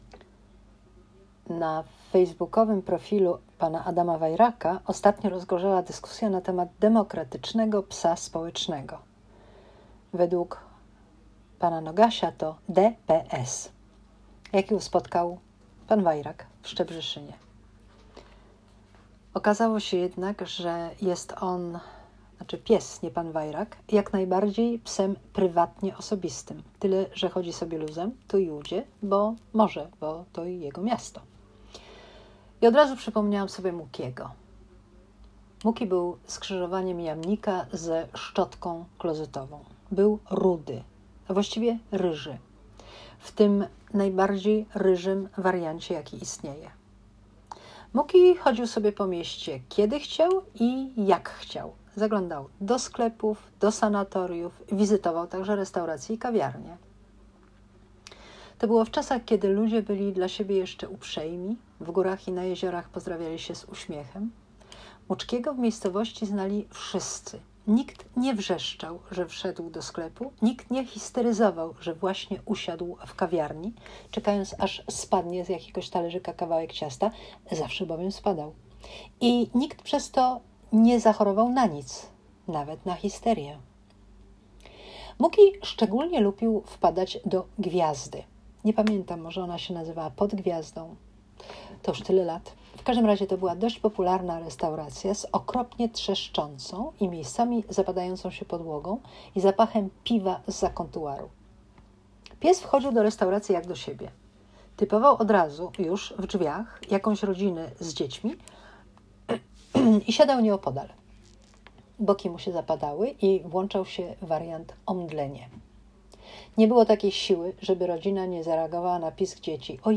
Powyżej – nagranie całego poniższego tekstu – robię eksperymenty z gadanym blogiem 😉